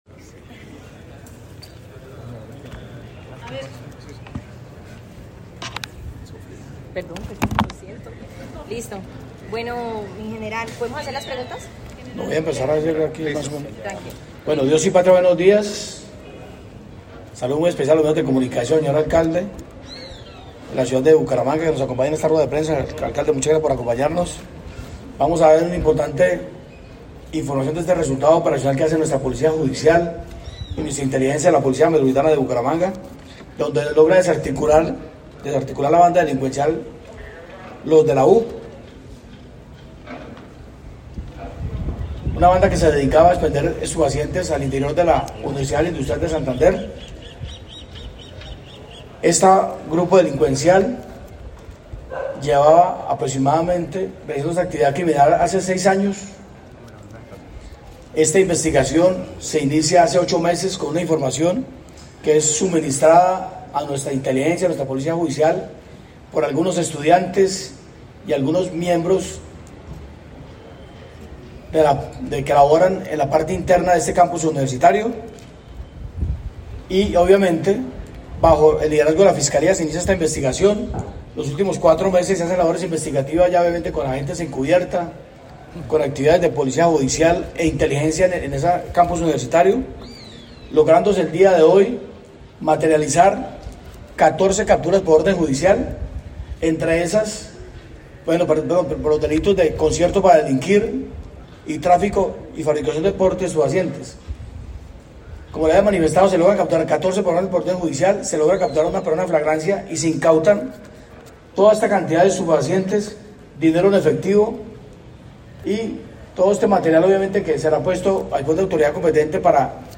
William Quintero Salazar, Comandante de la Policía Metropolitana de Bucaramanga